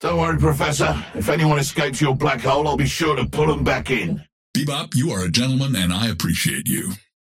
Bebop and Dynamo conversation 2